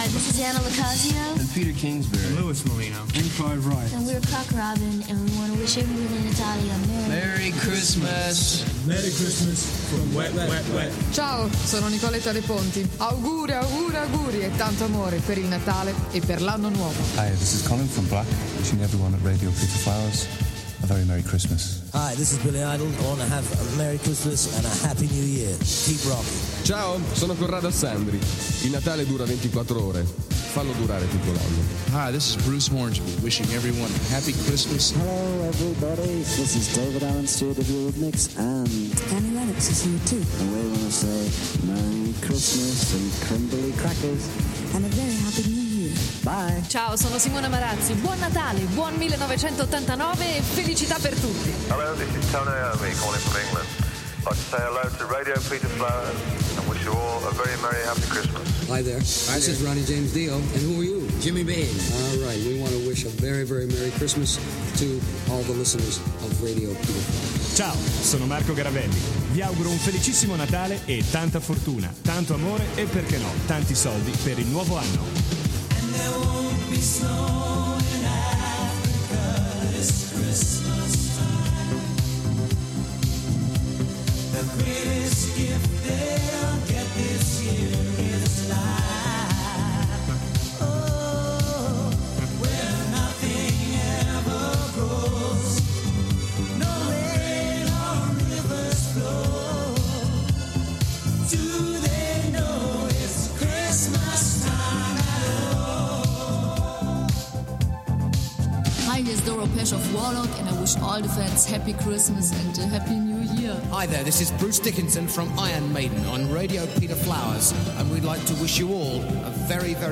All' allegato (3) gli auguri di deejay ed ospiti per le feste natalizie del 1988.